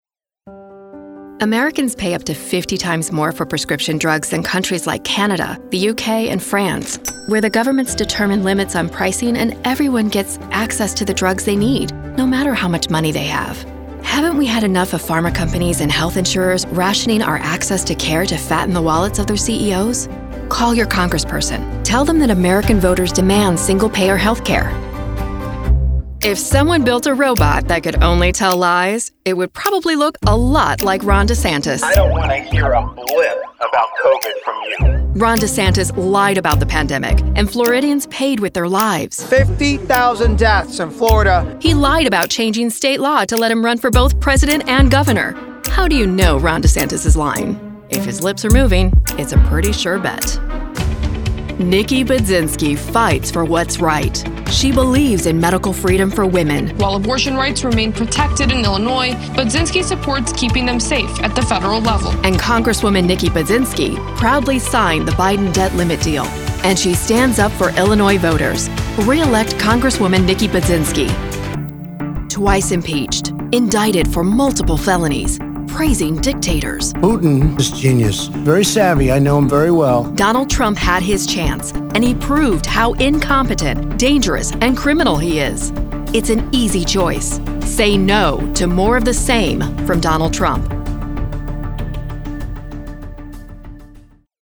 Political Voiceover
Issue Positive
Candidate Negative
Candidate Positive